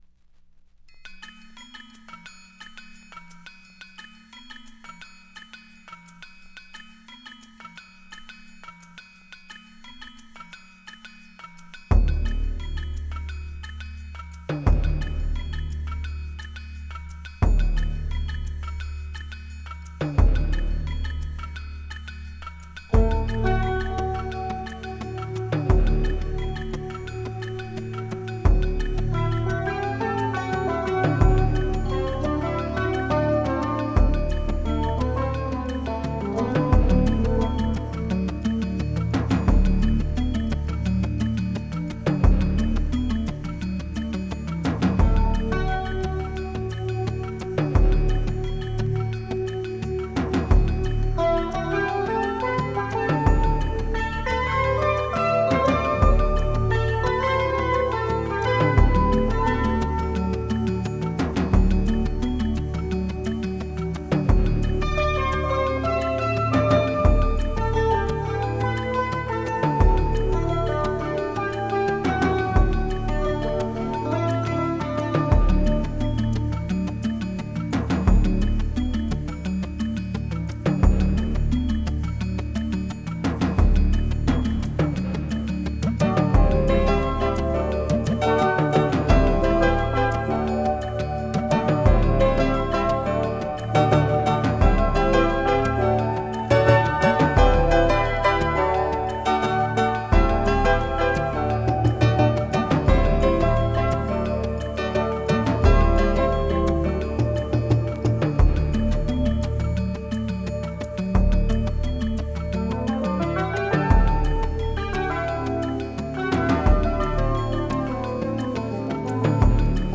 Here's a sample of the Ortofon Kontrapunkt B on my Rega P25: